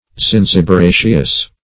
Zinziberaceous \Zin`zi*ber*a"ceous\, a.
zinziberaceous.mp3